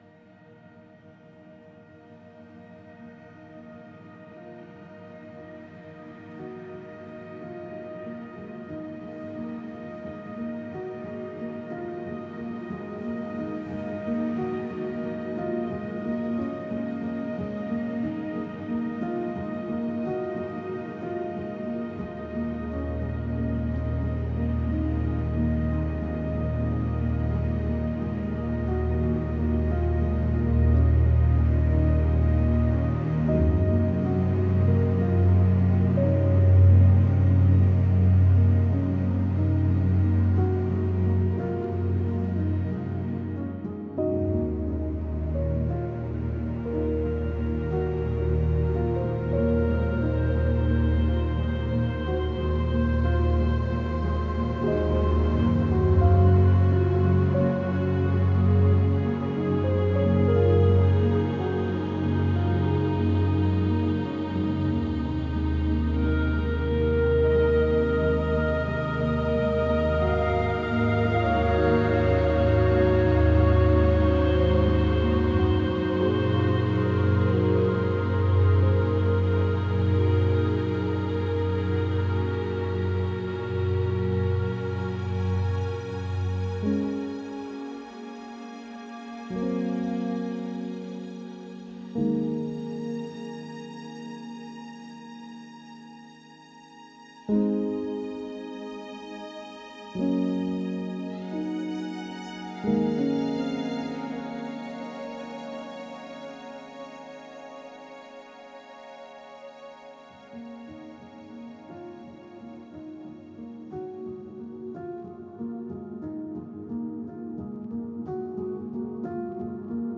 / 01 - Ambience / Safe House / Safe House 1 - Full - (Loop... 30 MiB Raw Permalink History
Safe House 1 - Full - (Loop).wav